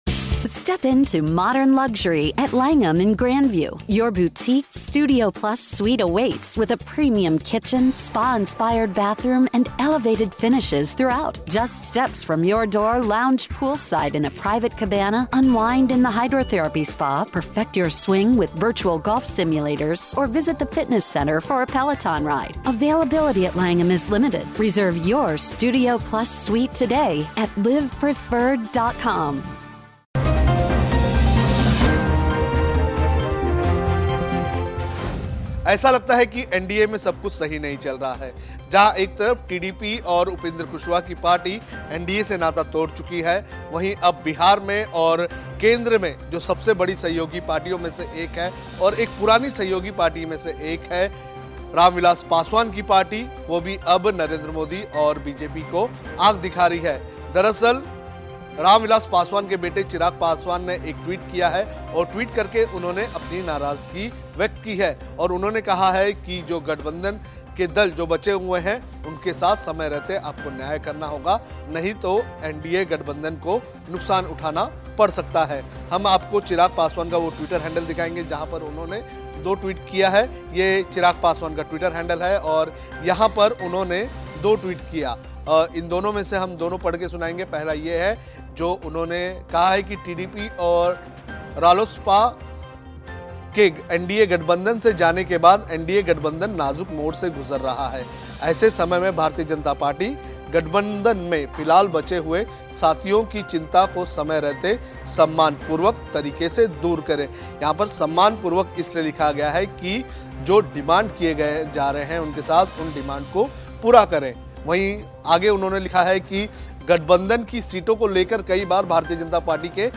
न्यूज़ रिपोर्ट - News Report Hindi / मोदी को डबल झटका, चिराग पासवान ने कहा सम्मान नहीं तो लगेगा गठबंधन को झटका